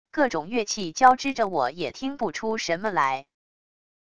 各种乐器交织着我也听不出什么来wav音频